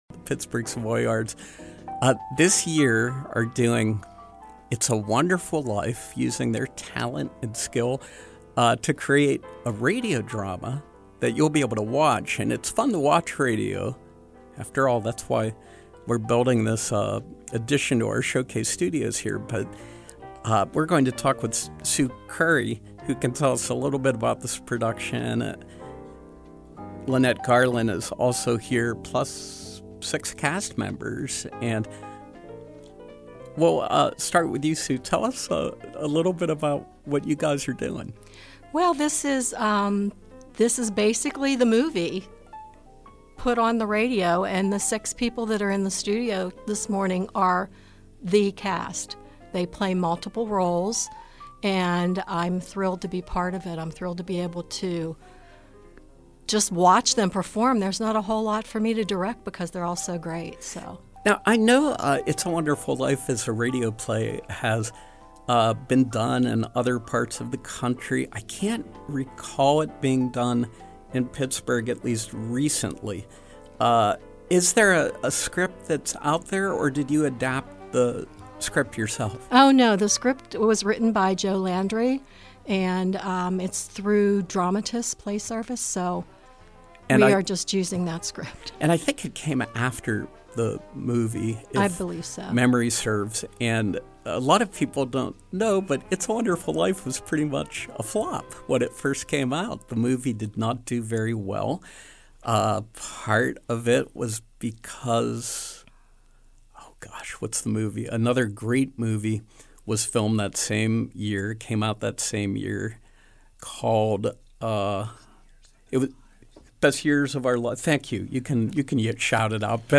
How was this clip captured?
In Studio Pop-Up: It’s a Wonderful Life, Pittsburgh Savoyards